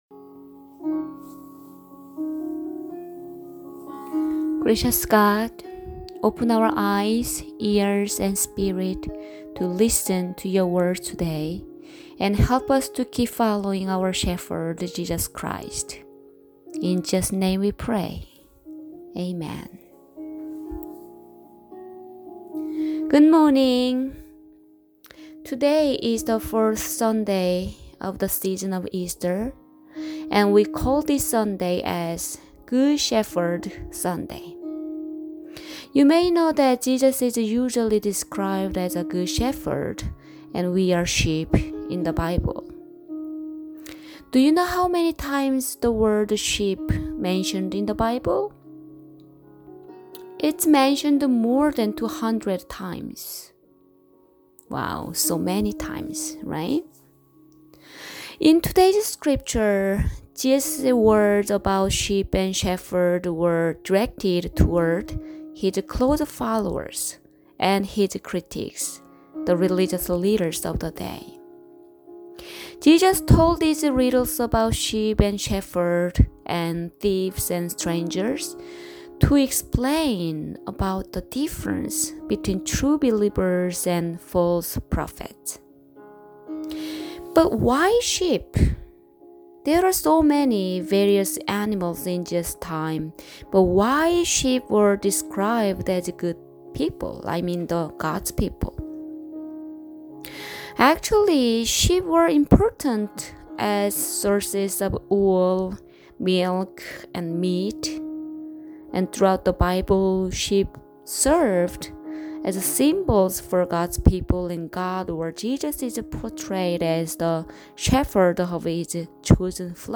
Service Type: Sermons